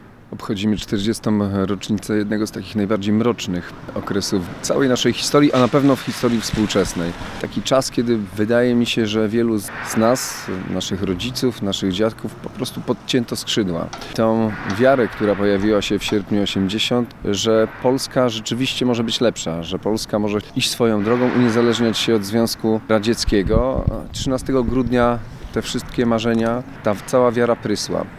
Marszałek Olgierd Geblewicz w hołdzie bohaterom przy bramie Stoczni Szczecińskiej.
Marszałek Olgierd Geblewicz oddał hołd bohaterom walki o wolną i demokratyczną Polskę. Dzisiejszego poranka złożył wiązankę kwiatów pod tablicą upamiętniającą poległych w Grudniu’70, przy bramie Stoczni Szczecińskiej.